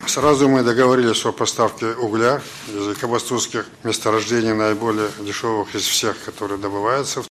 Нурсултан Назарбаев выступил в Киеве после встречи с Петром Порошенко